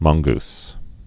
(mŏnggs, mŏn-)